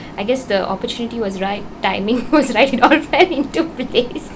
S1 = Hong Kong male S2 = Malaysian female
The problem seems to be that it was accompanied by laughter on the part of S2, and this reduces the intelligbility of it.